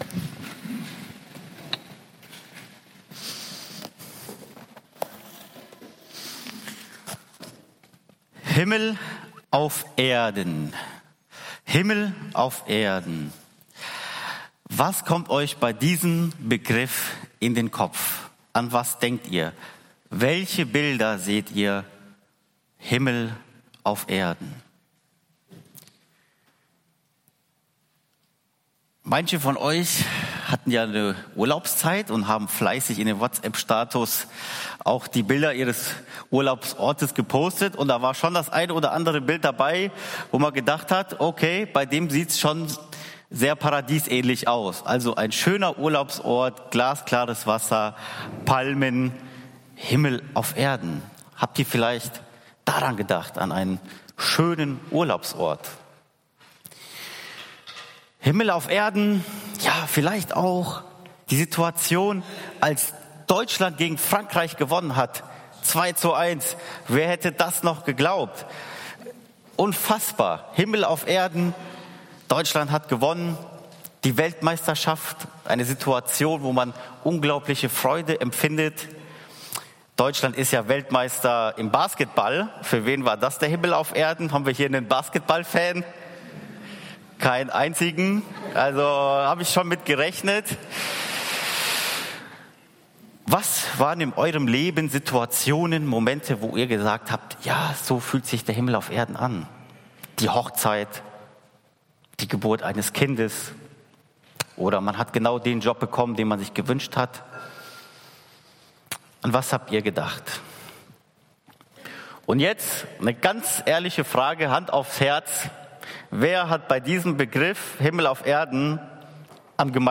EFG-Haiger Predigt-Podcast Himmlisch leben auf der Erde - geht das?